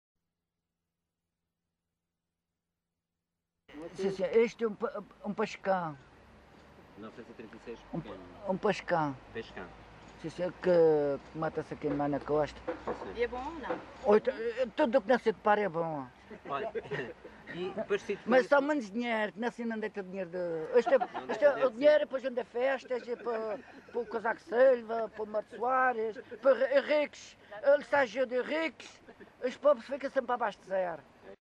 LocalidadeCâmara de Lobos (Câmara de Lobos, Funchal)